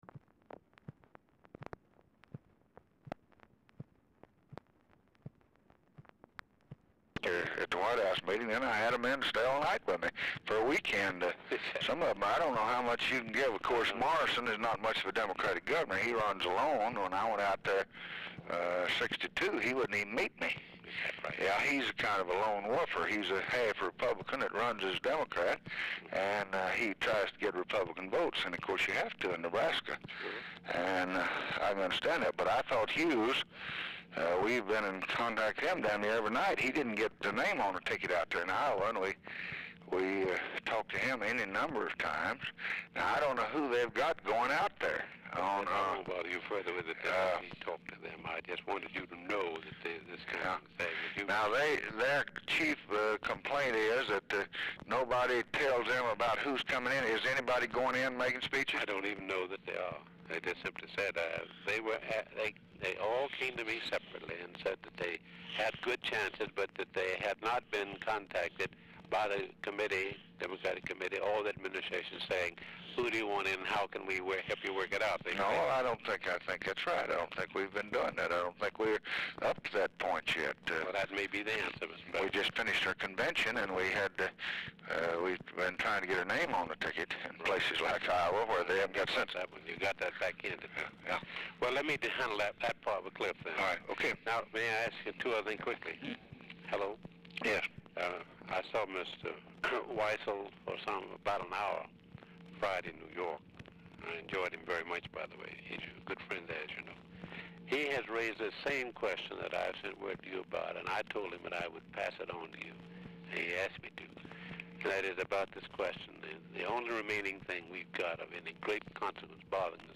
RECORDING STARTS AFTER CONVERSATION HAS BEGUN
Format Dictation belt
Specific Item Type Telephone conversation Subject Civil Rights Elections Labor National Politics